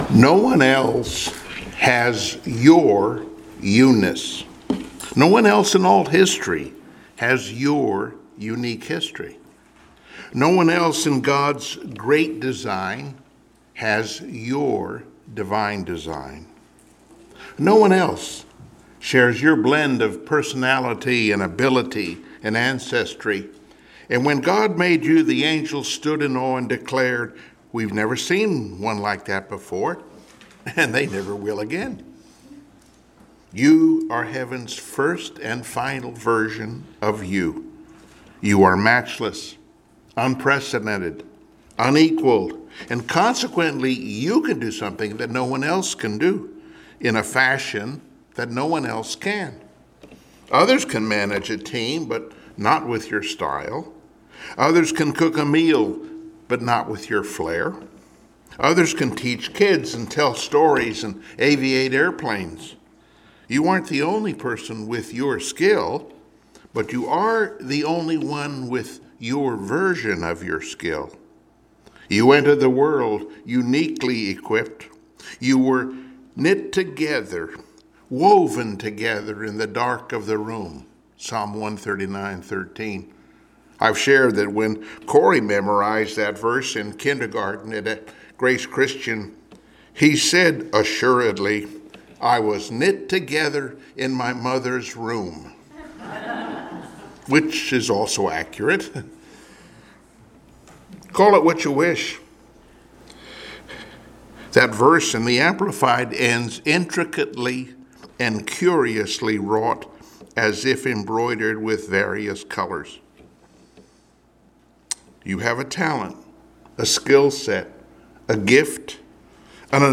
Passage: Joshua 11-13 Service Type: Sunday Morning Worship